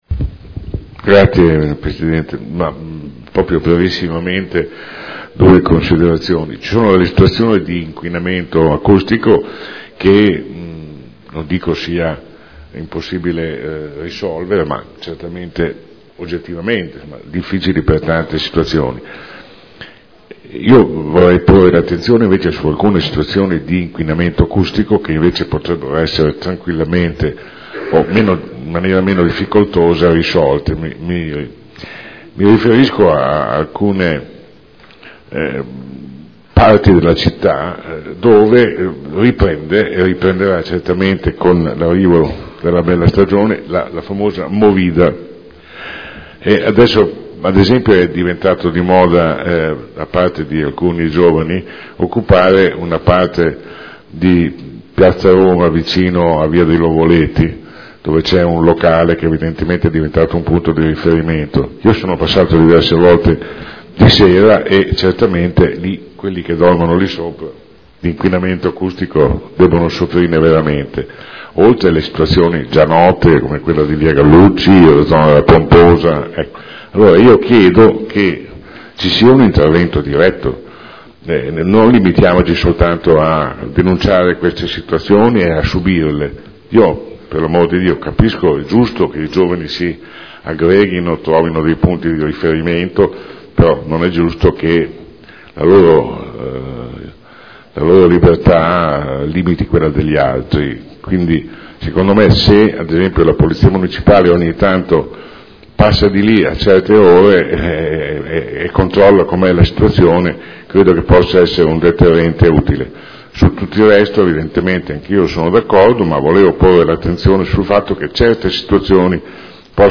Seduta del 24 febbraio. Proposta di deliberazione: Aggiornamento della classificazione acustica del territorio comunale – Adozione. Dibattito